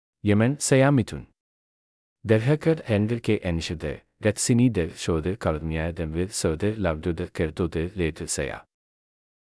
Midhun — Male Malayalam (India) AI Voice | TTS, Voice Cloning & Video | Verbatik AI
MidhunMale Malayalam AI voice
Voice sample
Listen to Midhun's male Malayalam voice.
Male
Midhun delivers clear pronunciation with authentic India Malayalam intonation, making your content sound professionally produced.